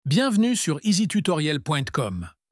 Voici l'exemple de l'audio que je vais utiliser dans ce tutoriel, il a été créé avec ElevenLabs.